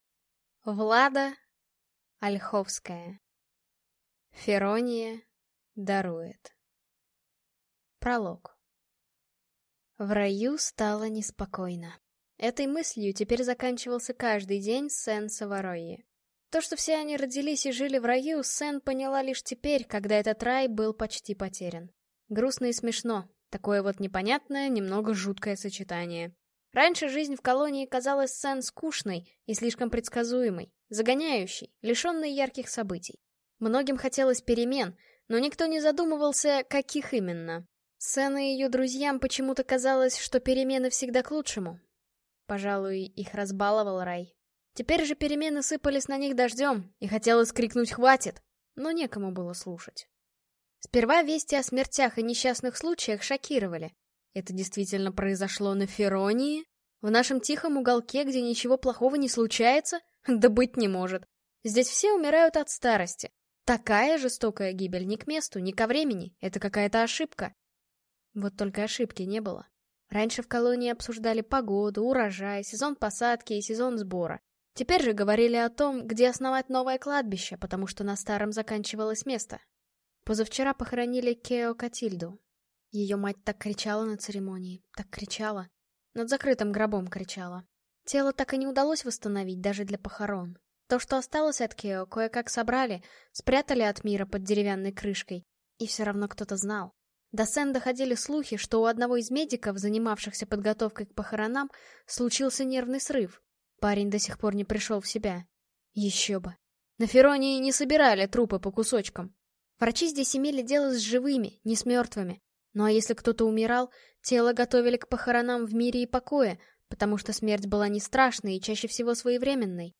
Аудиокнига Ферония дарует | Библиотека аудиокниг